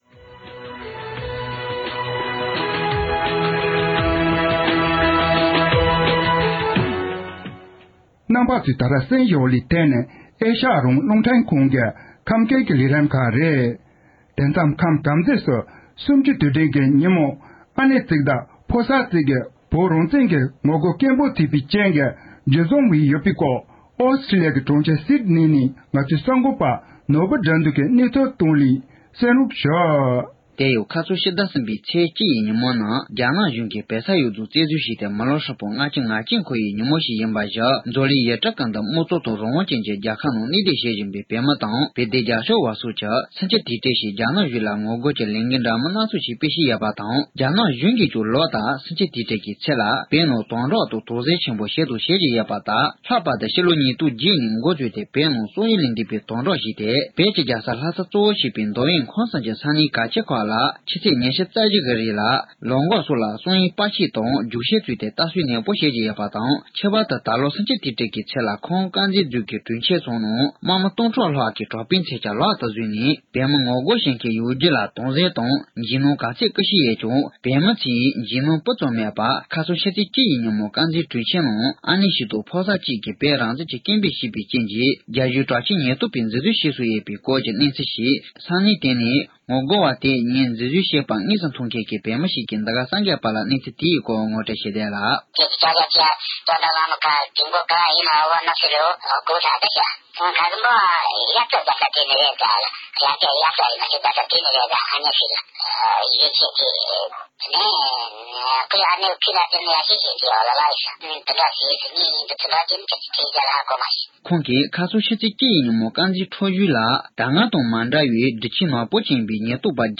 སྒྲ་ལྡན་གསར་འགྱུར།
༄༅༎ཉེ་ཆར་ཕྱི་ཚེས་བཅུའི་ཉིན་བོད་ཁམས་དཀར་མཛེས་རྫོང་དུ་བོད་མི་གཉིས་ཀྱིས་རྒྱ་ནག་གཞུང་ལ་ངོ་རྒོལ་སྐད་འབོད་བྱས་པར་བརྟེན།རྒྱ་གཞུང་དྲག་ཆས་ཉེན་རྟོག་པས་ཁོང་གཉིས་འཛིན་བཟུང་བྱས་པའི་སྐོར།བོད་ནས་བོད་མི་ཞིག་གིས་ཁུངས་ལྡན་གྱི་གནས་ཚུལ་ངོ་སྤྲོད་གནང་བར་གསན་རོགས༎